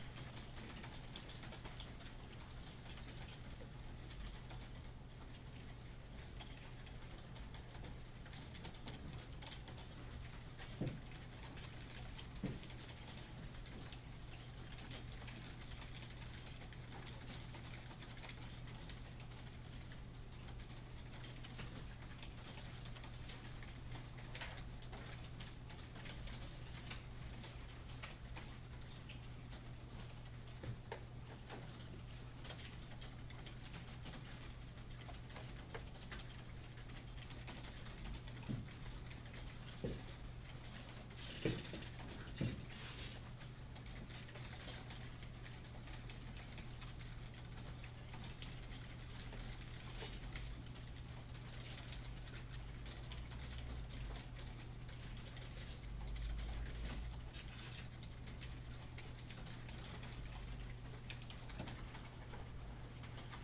Rattle of rapid typing